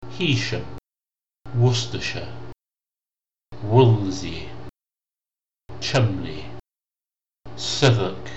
Here is a recording of me saying the names of five places in England. Each is spelt in a way that doesn’t match the pronunciation.